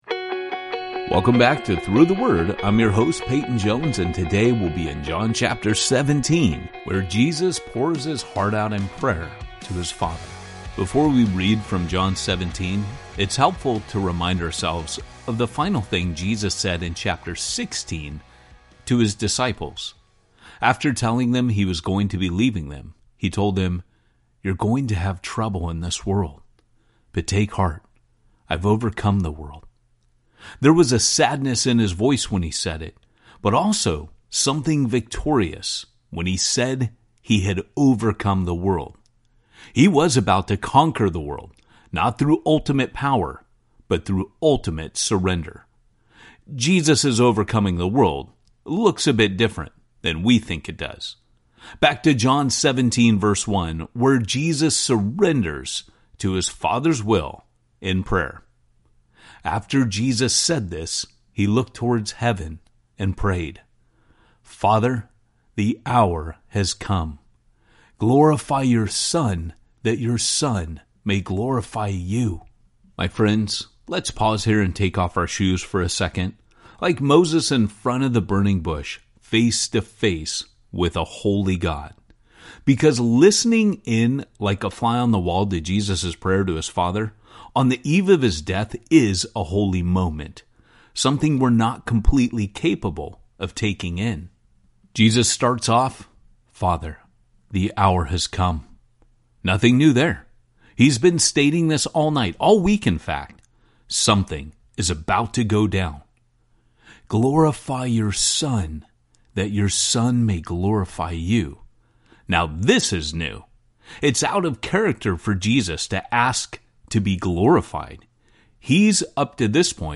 The story comes alive each day as Through the Word’s ten-minute audio guides walk you through each chapter with clear explanation and engaging storytelling.